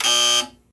follyBuzzer.ogg